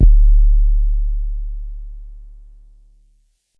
Check808_YC.wav